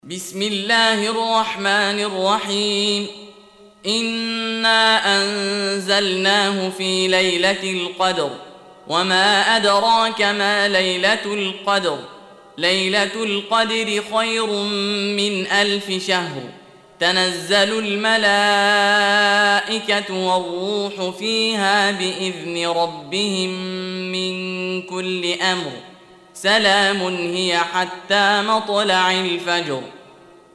Audio Quran Tarteel Recitation
Surah Repeating تكرار السورة Download Surah حمّل السورة Reciting Murattalah Audio for 97. Surah Al-Qadr سورة القدر N.B *Surah Includes Al-Basmalah Reciters Sequents تتابع التلاوات Reciters Repeats تكرار التلاوات